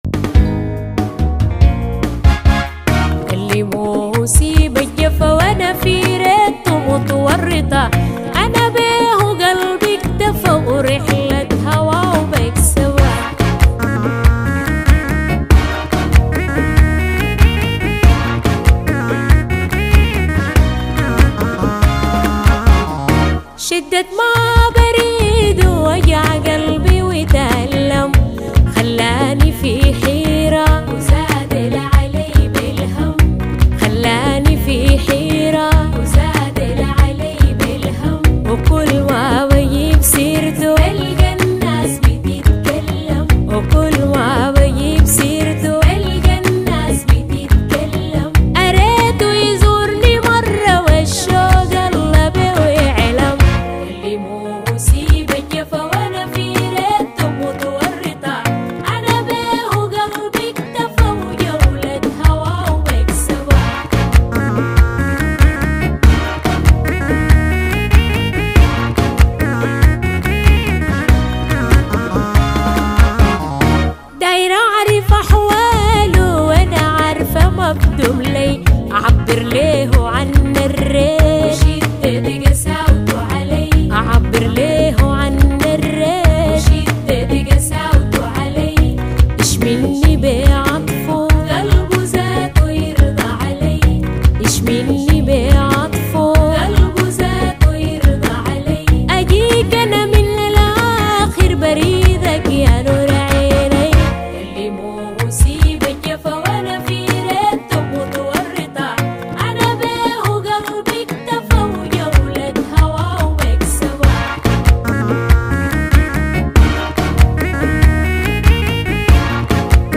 اغاني سودانية